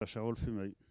Elle provient de Sallertaine.
Locution ( parler, expression, langue,... )